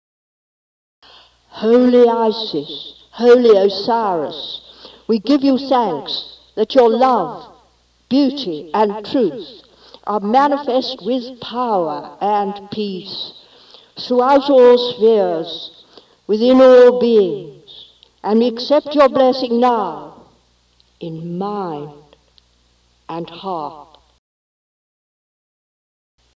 Thanksgiving: